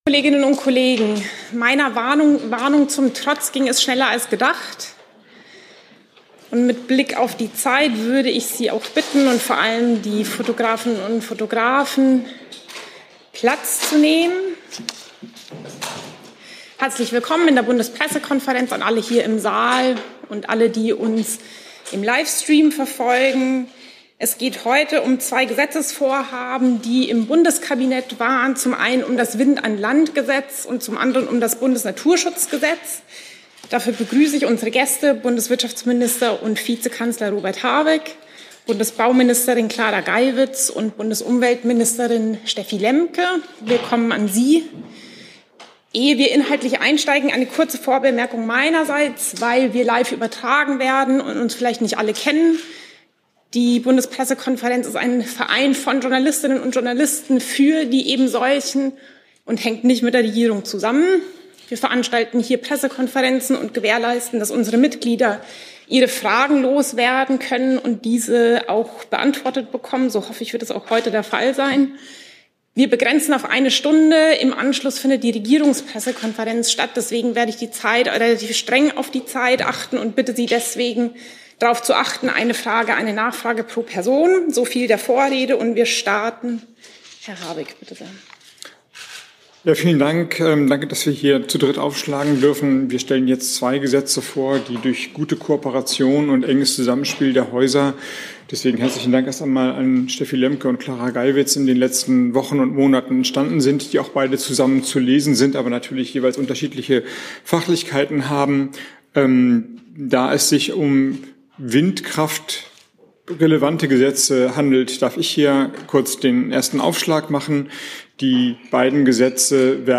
00:01:28 Statement Wirtschaftsminister Robert Habeck (Die Grünen)